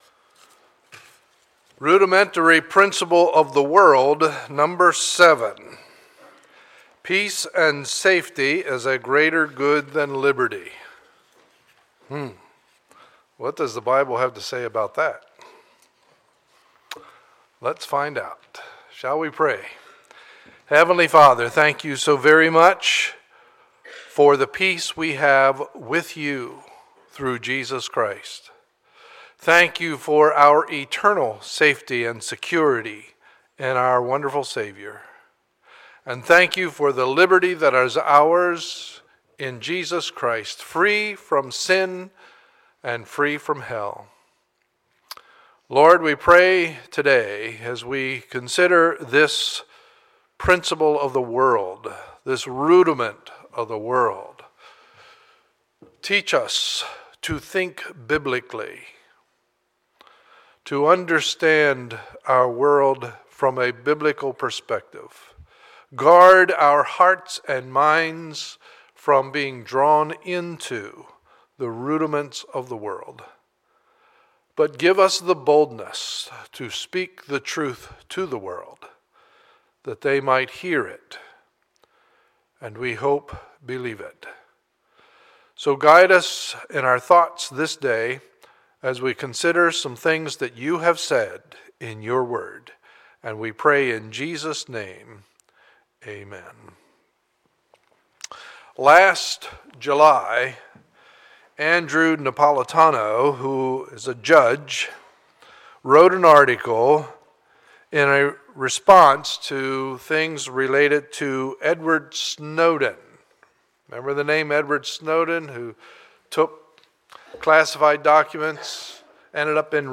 Sunday, April 27, 2014 – Morning Service